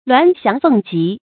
鸞翔鳳集 注音： ㄌㄨㄢˊ ㄒㄧㄤˊ ㄈㄥˋ ㄐㄧˊ 讀音讀法： 意思解釋： 比喻優秀的人才匯聚到一起。